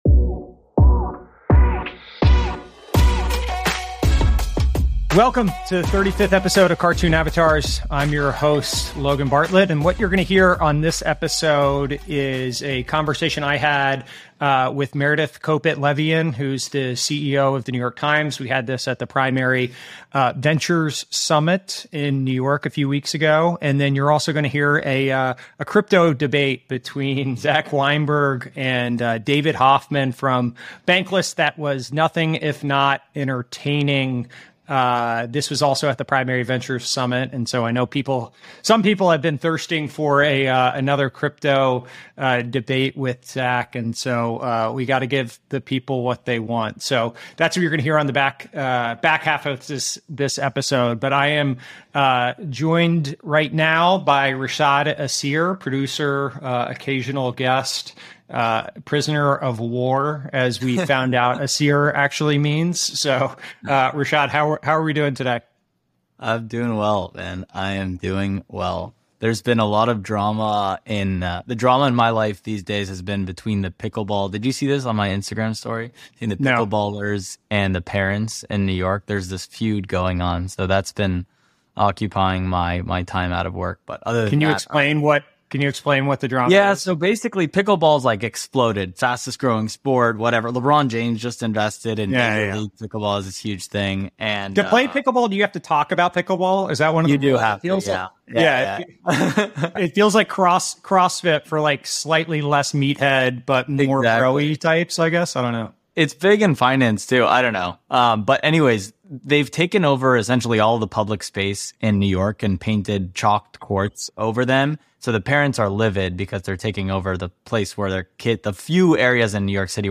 In episode 35 we have the very first live and on-stage Cartoon Avatars from Primary's New York City Summit.